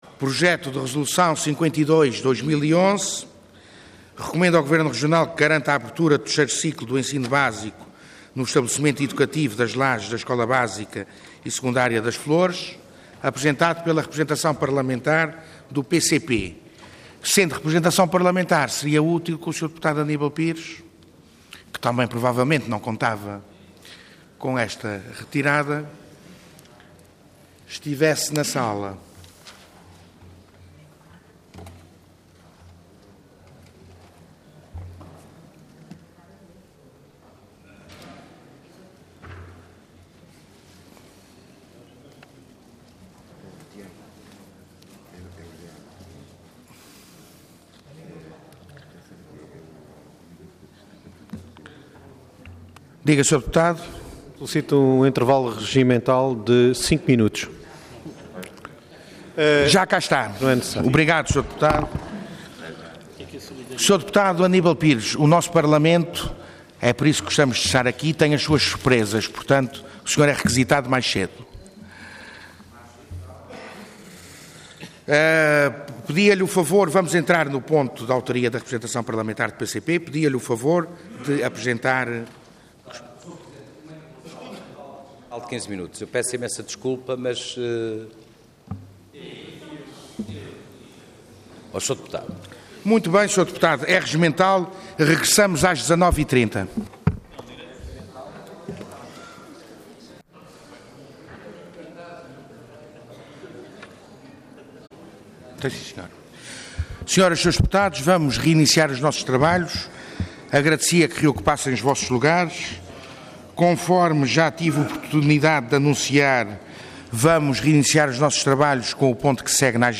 Intervenção Projeto de Resolução Orador Aníbal Pires Cargo Deputado Entidade PCP